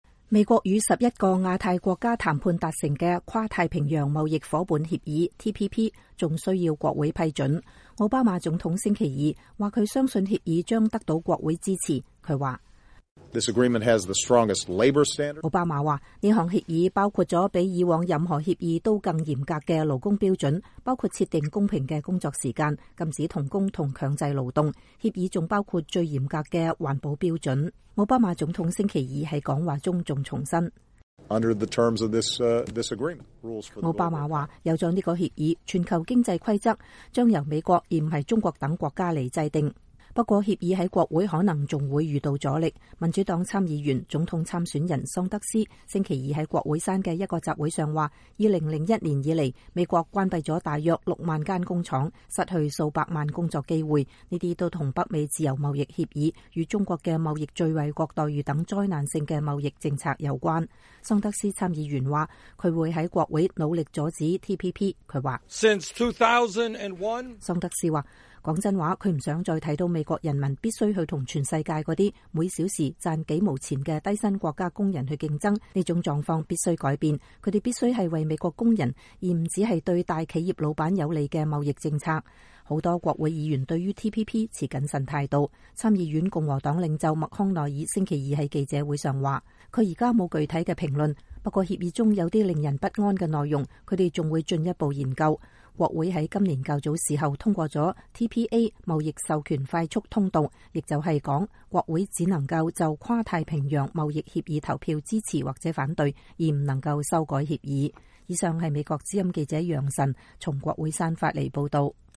民主黨參議員、總統參選人桑德斯（Senator Bernie Sanders）星期二在國會山的一個集會上說“2001年以來，美國關閉了大約6萬個工廠，失去數百萬工作機會，這些都和北美自 由貿易協議，與中國的貿易最惠國待遇等災難性的貿易政策有關。
參議院共和黨領袖麥康奈爾星期二在記者會上說：“我現在沒有具體的評論，不過協議中有一些令人不安的內容，我們還會進一步研究。